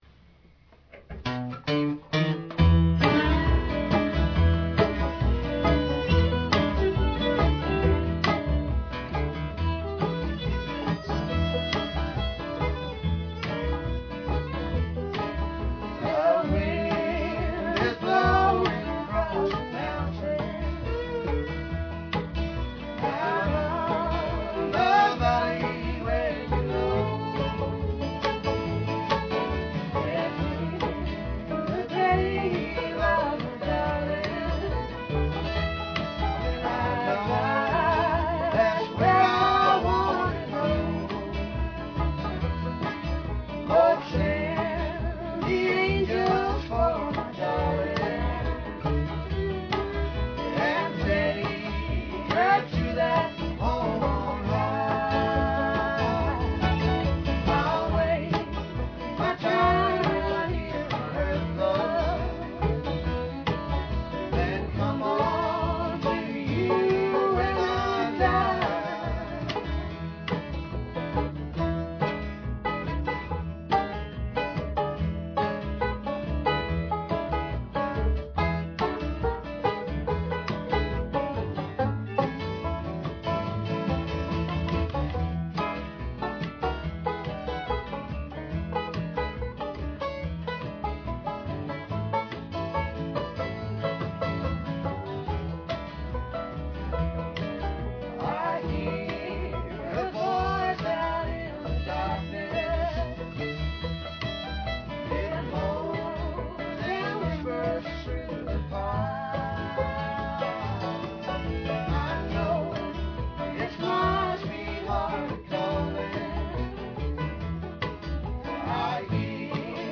Impromptu performance